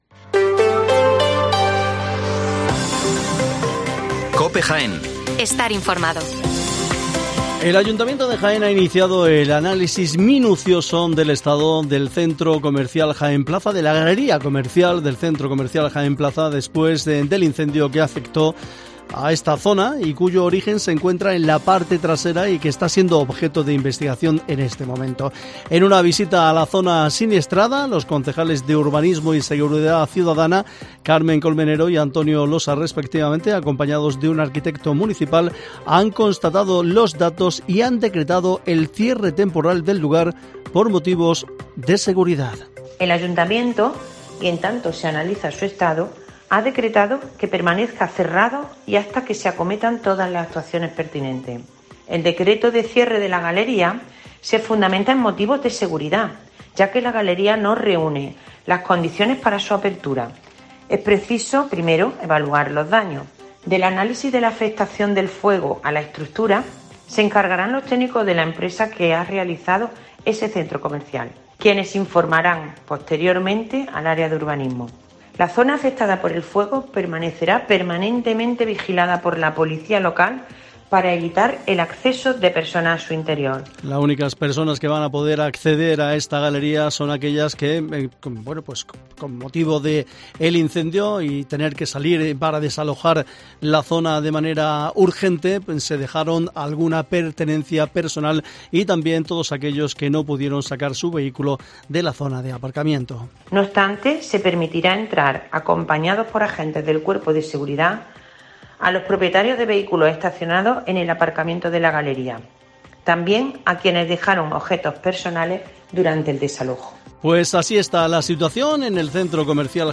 Informativo Mediodía COPE